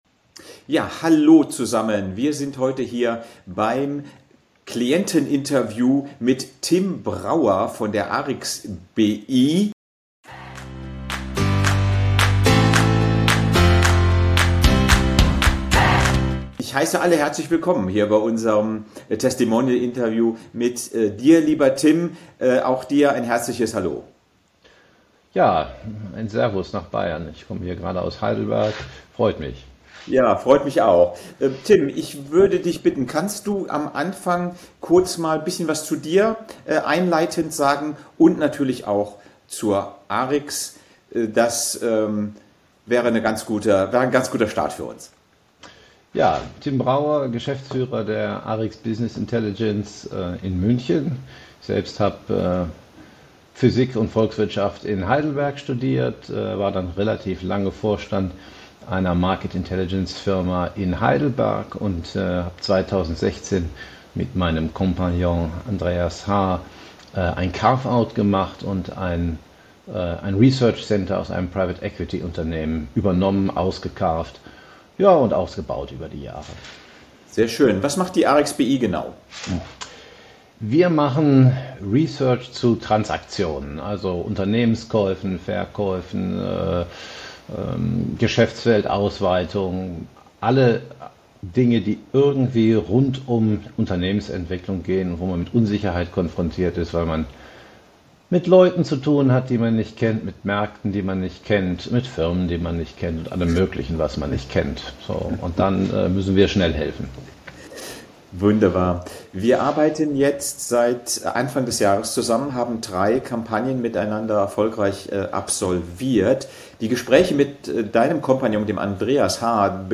Nr. 19 - Effektiv Kunden gewinnen und Produktivität steigern – Interview